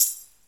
normal-slidertick.wav